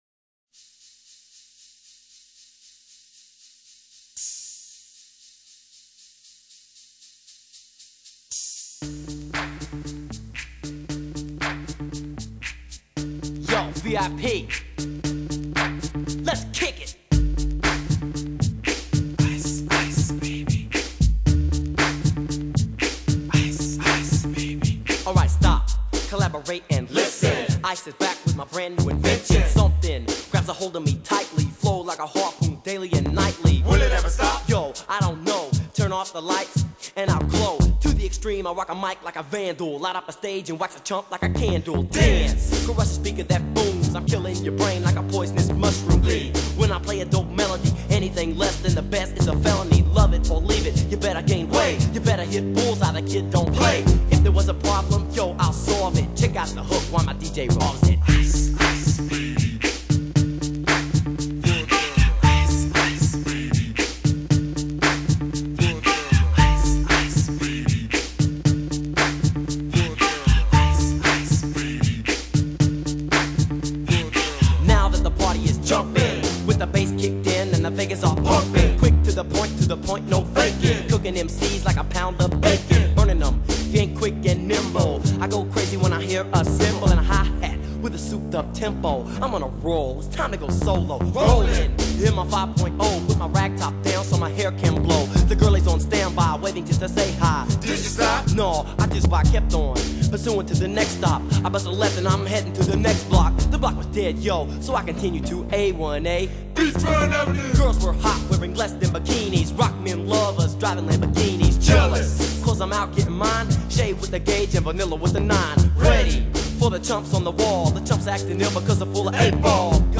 rapper
thanks to the pulsating bass riff